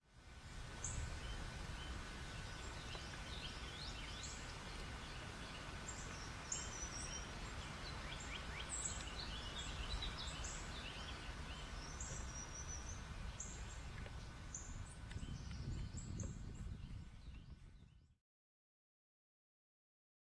FEMALE Black-and-white Warbler  MOV  MP4  M4ViPOD  WMV